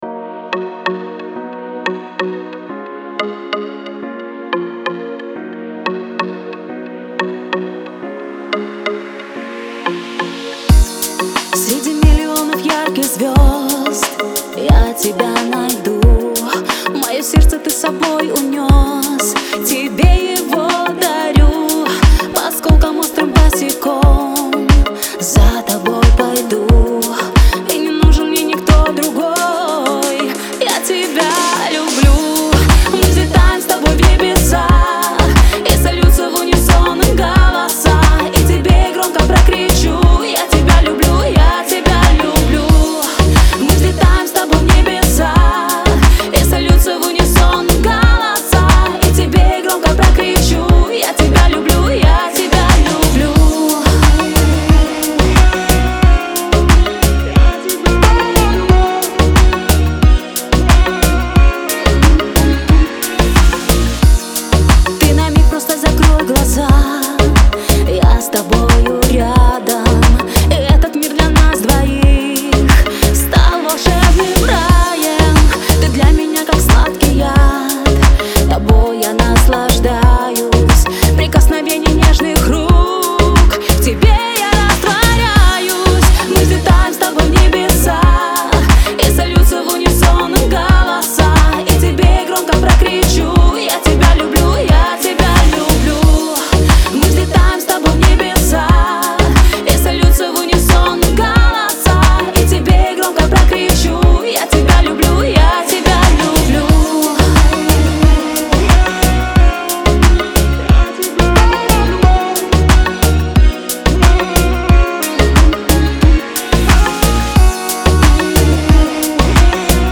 это трек в жанре поп с элементами романтической баллады.
Звучание трека отличается мелодичностью и мягкостью